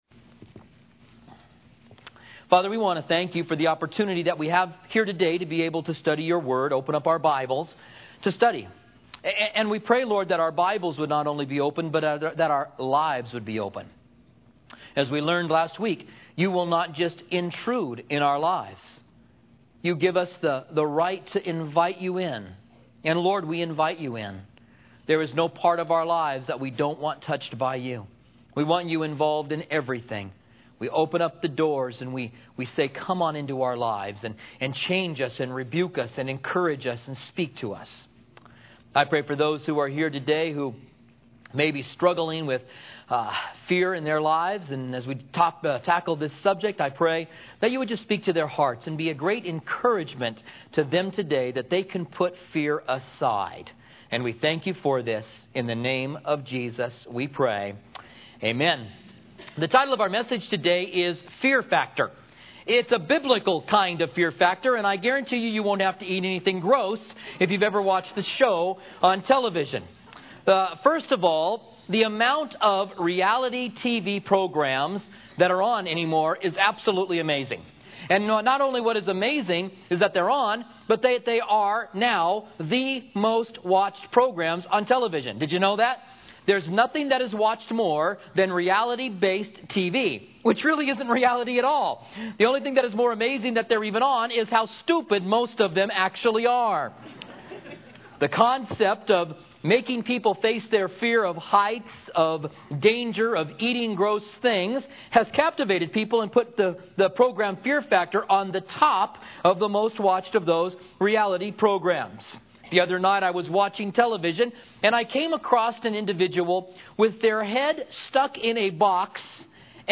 teaches a study in Proverbs starting today in chapter 3, verse 20.